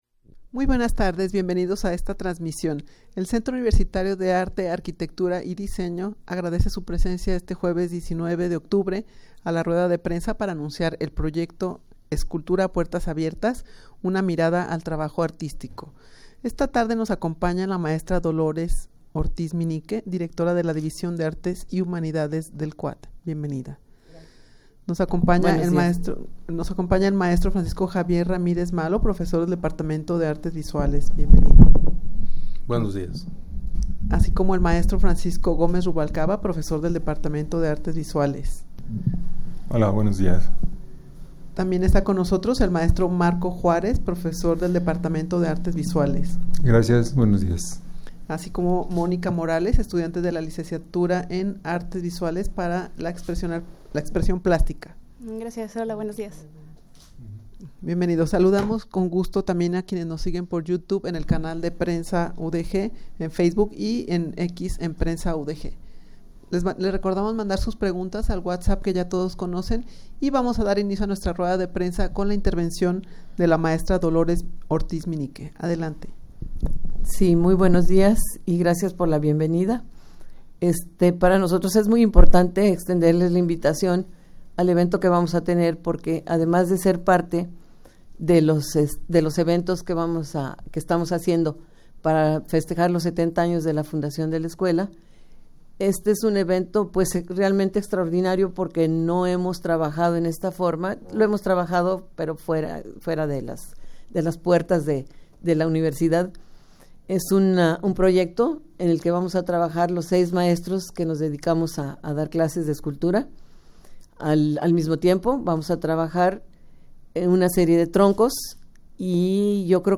rueda-de-prensa-para-anunciar-el-proyecto-escultura-a-puertas-abiertas-una-mirada-al-trabajo-artistico.mp3